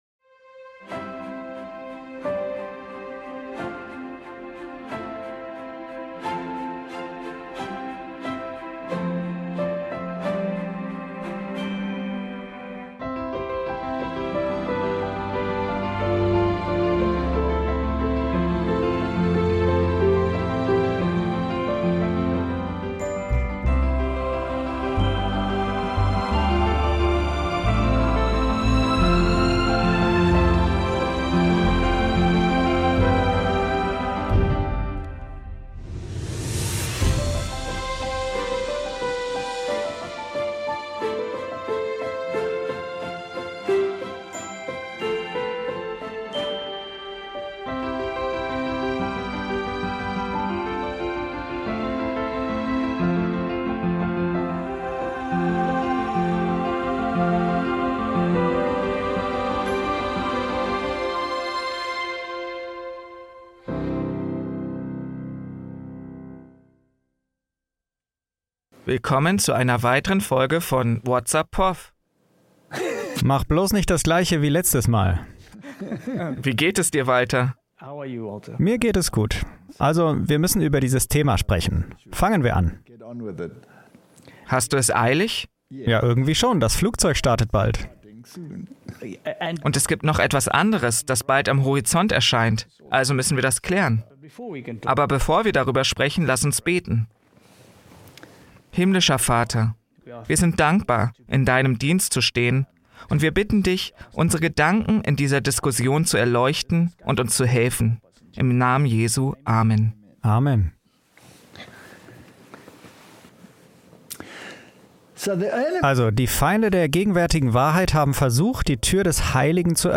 Whats Up, Prof? (Voice Over)